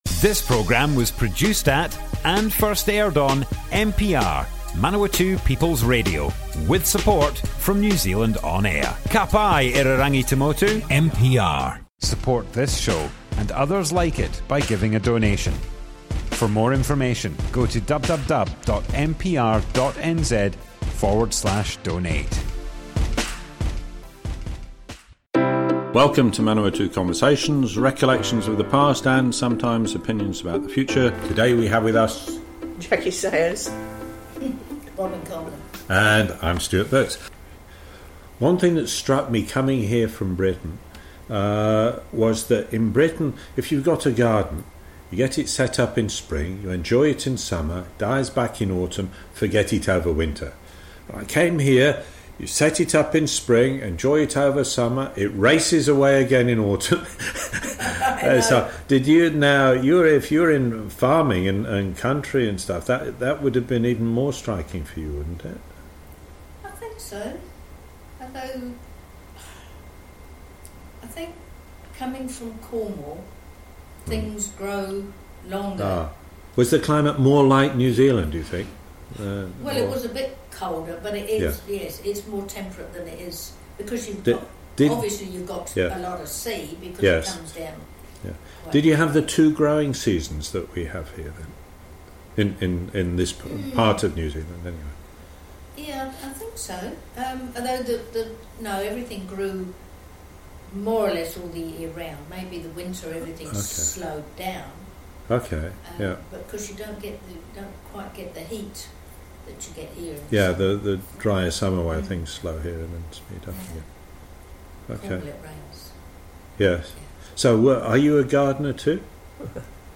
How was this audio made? Manawatu Conversations More Info → Description Broadcast on Manawatu People's Radio, 10 August 2021.